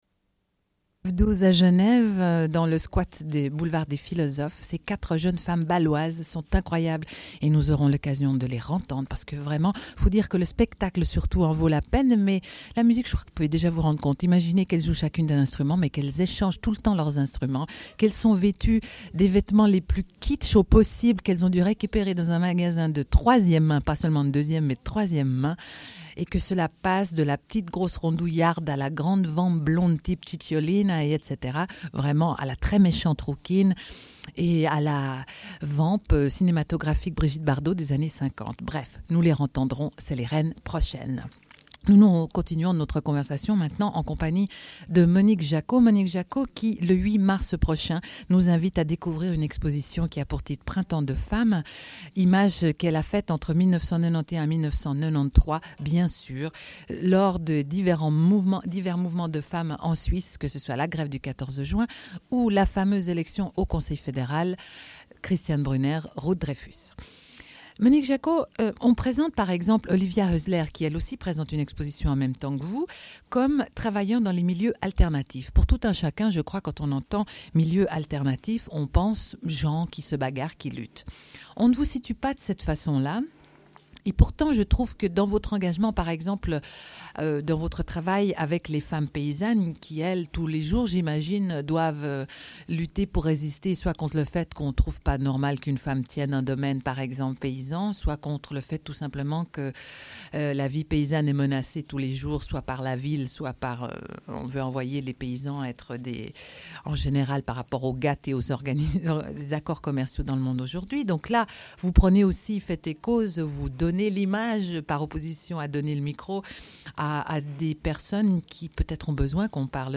Une cassette audio, face B29:11